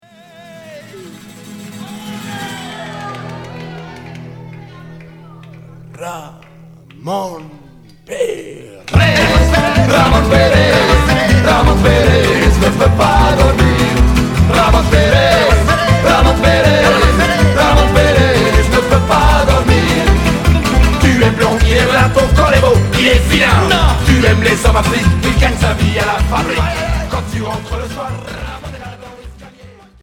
Folk Rock Deuxième 45t retour à l'accueil